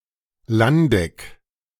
Landeck (German pronunciation: [ˈlandɛk]
De-Landeck.ogg.mp3